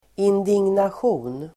Ladda ner uttalet
Folkets service: indignation indignation substantiv, indignation Uttal: [indingnasj'o:n] Böjningar: indignationen Synonymer: förargelse, förtrytelse Definition: moralisk upprördhet outrage substantiv, indignation , harm